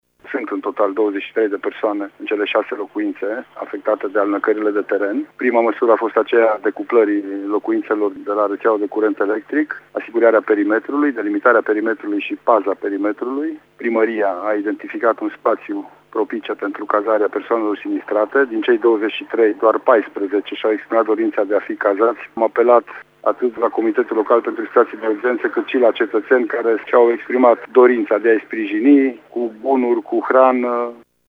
Există și un drum județean afectat de aluviuni. Jandarmii vor asigura, cel puțin până luni, perimetrul afectat de alunecările de teren, a precizat prefectul Lucian Goga: